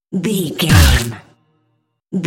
Dramatic hit deep electronic wood
Sound Effects
Atonal
heavy
intense
dark
aggressive